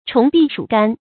蟲臂鼠肝 注音： ㄔㄨㄙˊ ㄅㄧˋ ㄕㄨˇ ㄍㄢ 讀音讀法： 意思解釋： 比喻極微小而無價值的東西。